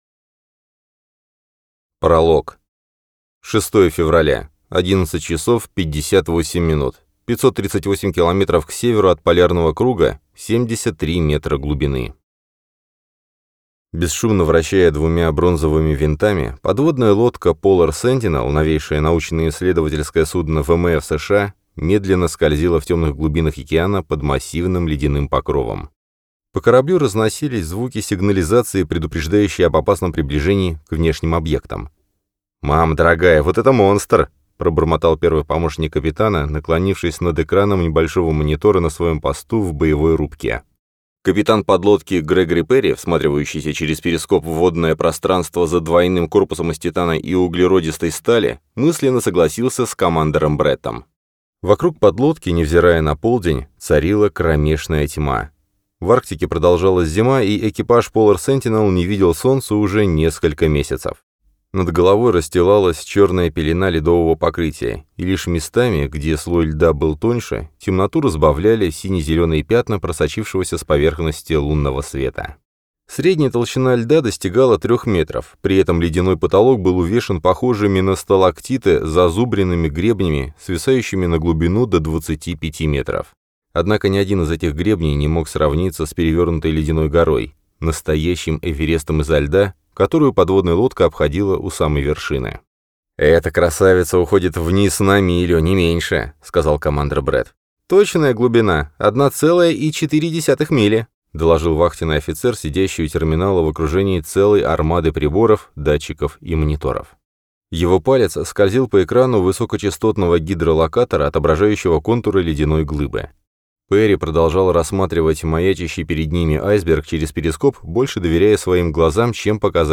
Аудиокнига Айсберг | Библиотека аудиокниг